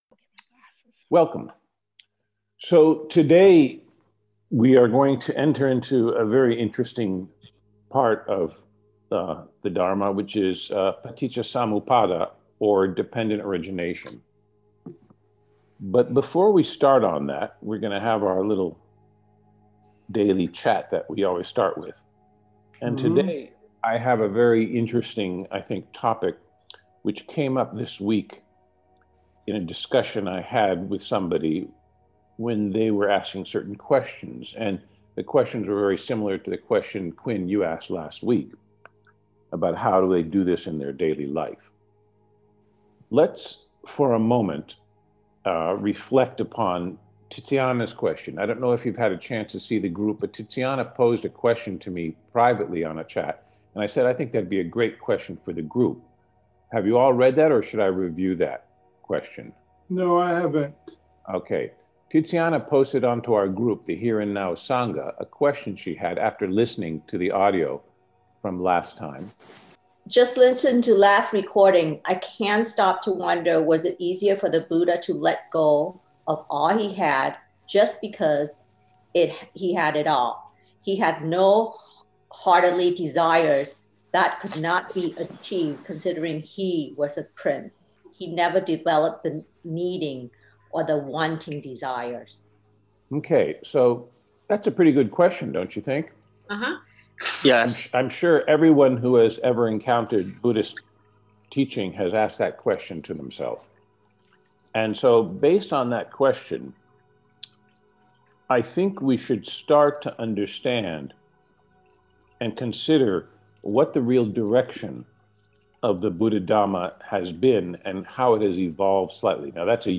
Path to Wisdom :: Conversation